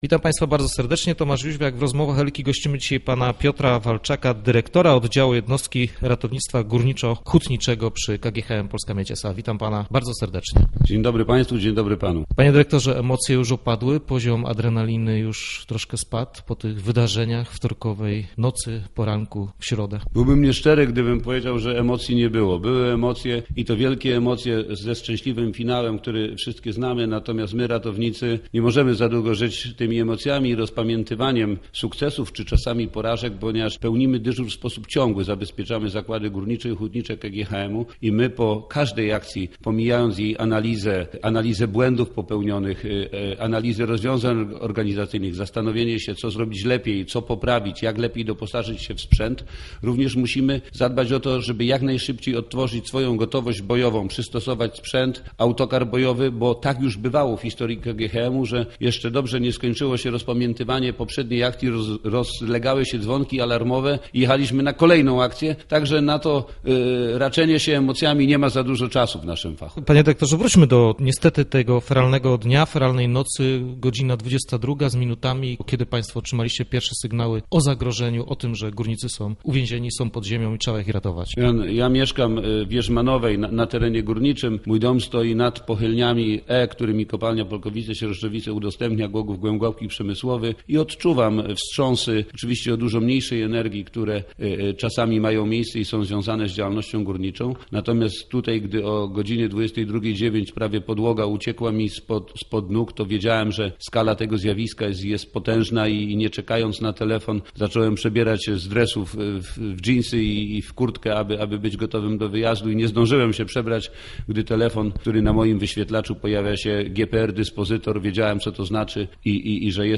To była jedna z największych akcji poszukiwawczych w historii Polskiej Miedzi. O emocjach i trudach ekstremalnej wyprawy ze szczęśliwym finałem wydobycia 19 górników rozmawialiśmy